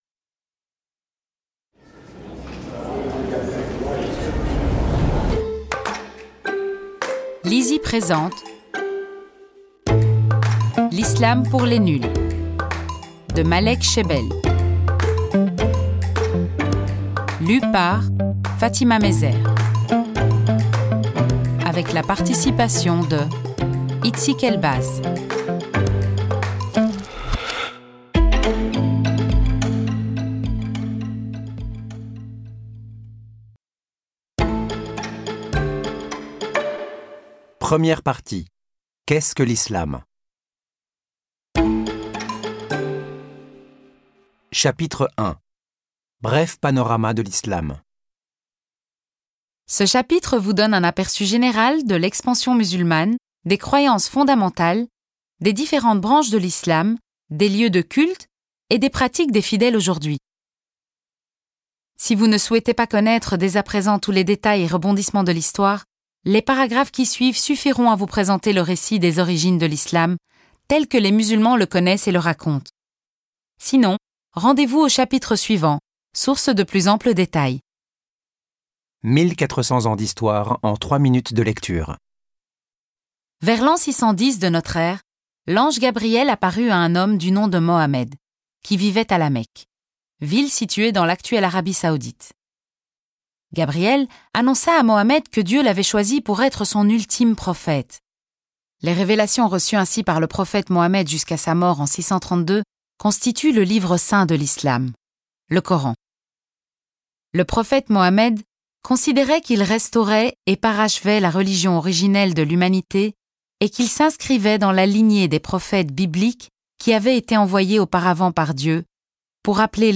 je découvre un extrait - L'islam pour les Nuls de Malek Chebel
Tu apprendras comment l'ange Gabriel est apparu à Mohammed à La Mecque, tu découvriras l'oeuvre littéraire et culturelle qu'est le Coran, tu en sauras plus sur les valeurs de l'islam, tu comprendras le rapport de cette religion au monde d'aujourd'hui...et bien des choses encore ! Que tu sois musulman ou simplement curieux, si tu as envie de découvrir l'islam sous toutes ses coutures, ce livre audio est celui qu'il te faut !